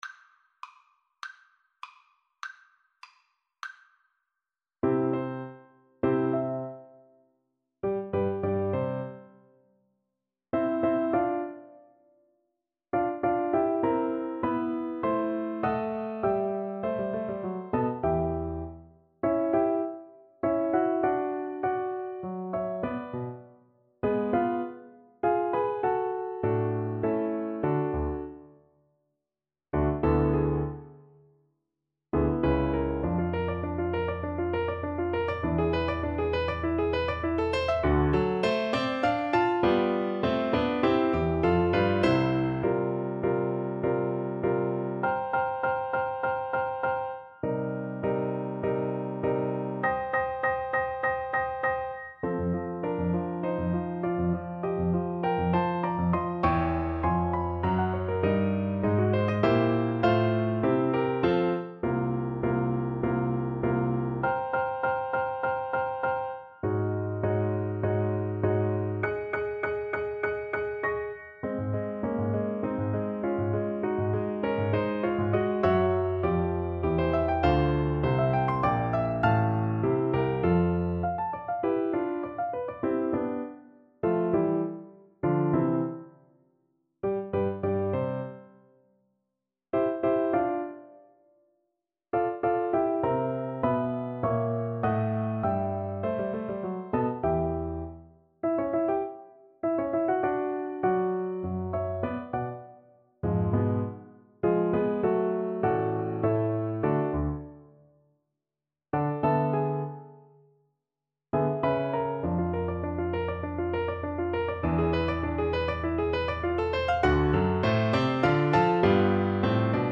~ = 200 Allegro Animato (View more music marked Allegro)
2/2 (View more 2/2 Music)
Classical (View more Classical Flute Music)